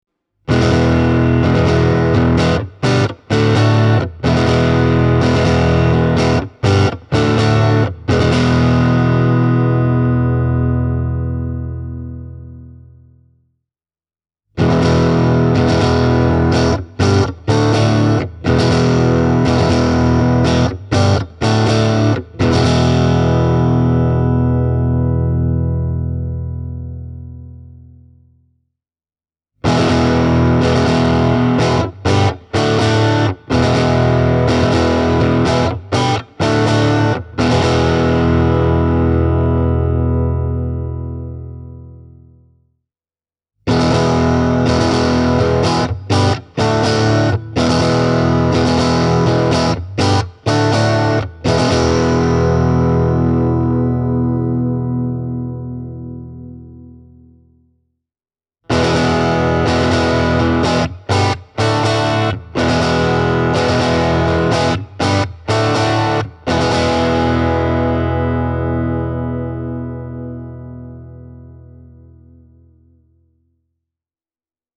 Perussointi on helisevä ja hieman pureva yksittäisiä mikkejä valitessa, ja se muuttuu kuivemmaksi ja ontoksi silloin kun kaksi mikrofonia on samanaikaisessa käytössä.
Tweed-tyylinen kombo, särö:
fender-am-pro-stratocaster-e28093-tweed-grit.mp3